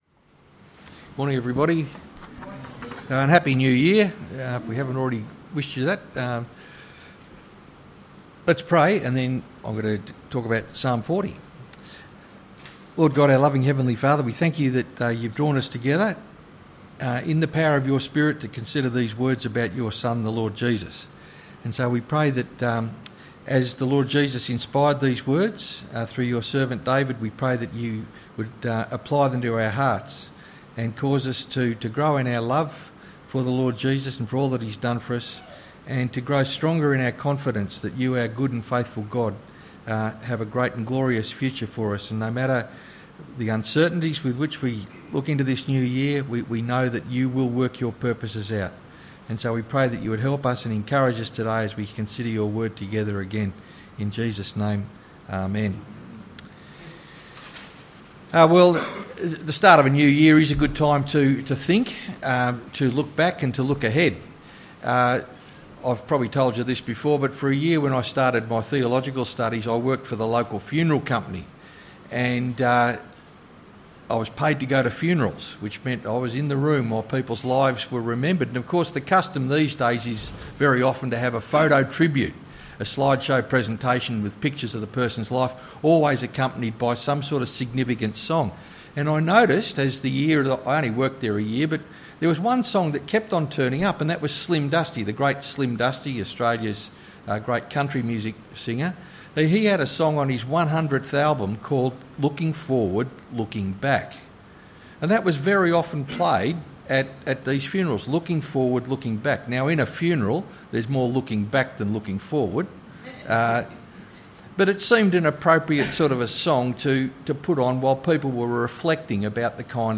Sermon: 1 Samuel 8:1-22